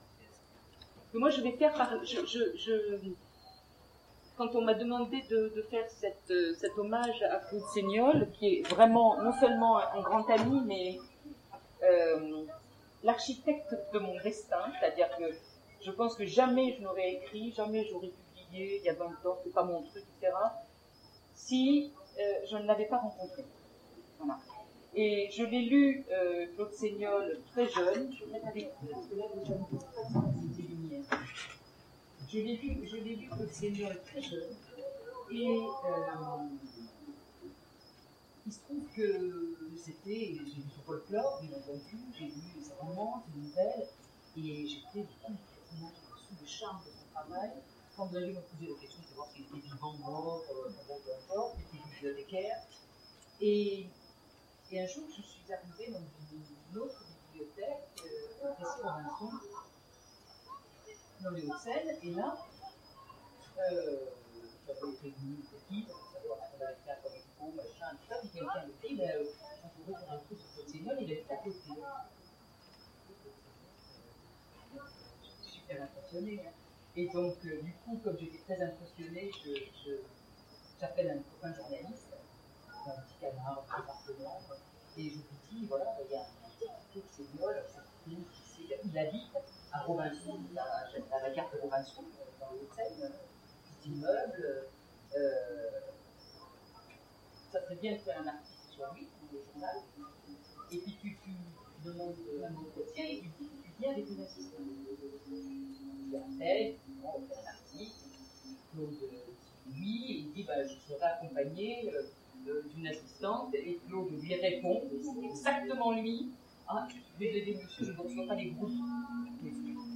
Mots-clés Hommage Conférence Partager cet article